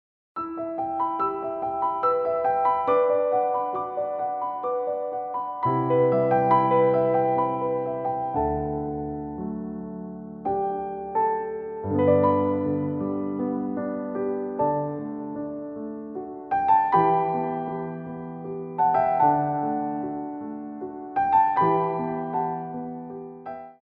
Adagio
3/4 (8x8)